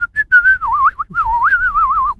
pgs/Assets/Audio/Animal_Impersonations/bird_sparrow_tweet_01.wav at master
bird_sparrow_tweet_01.wav